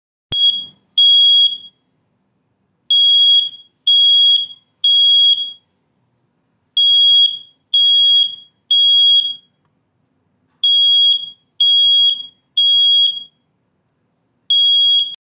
Alertamiento de detectores de humo
Alertamiento-detector-de-humo.mp3